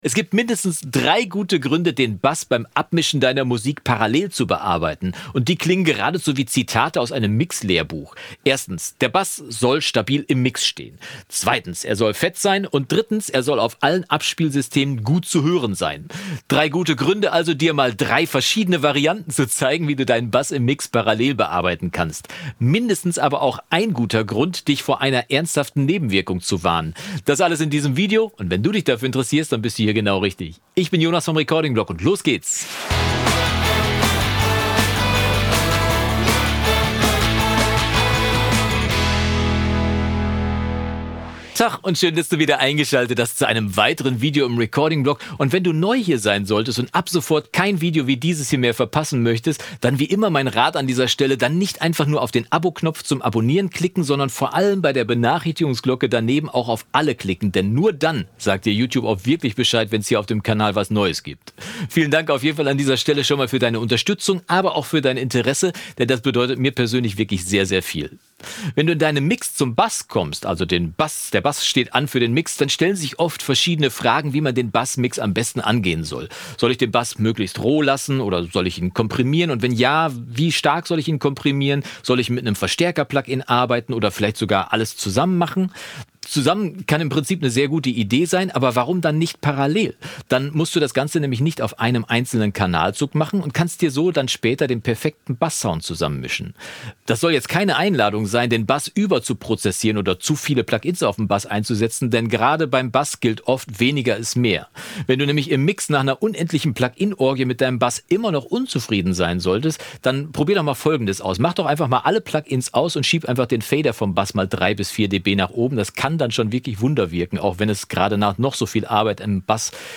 Letzte Episode Einfach parallel: Bass fett abmischen auf mehreren Spuren | Abmischen Tutorial | Recording-Blog 124 27.